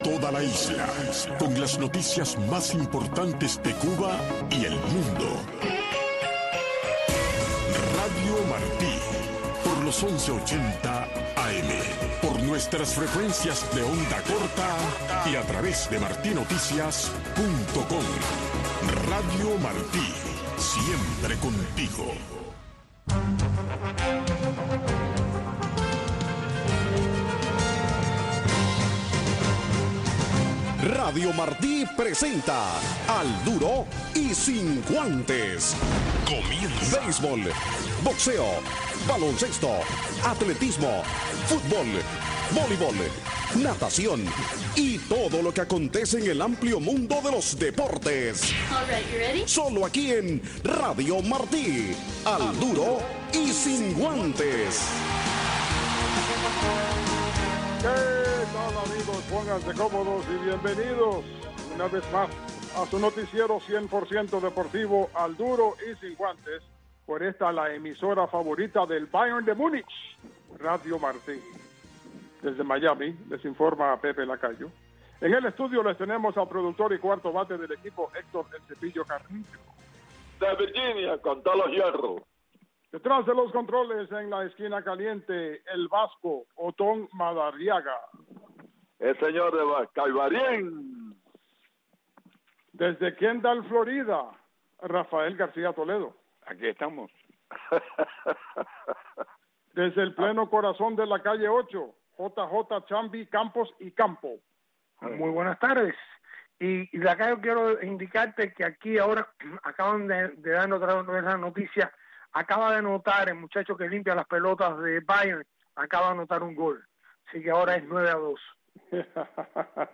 Noticiero Deportivo